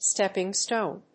音節stép・ping stòne 発音記号・読み方
/ˈstɛpɪˌŋston(米国英語), ˈstepɪˌŋstəʊn(英国英語)/